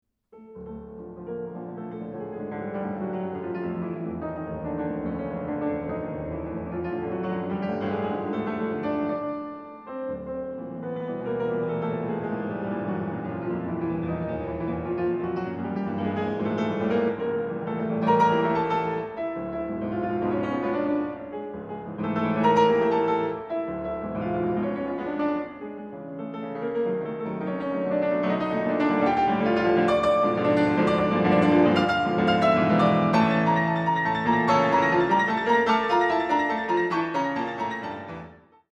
A-dur Allegro grazioso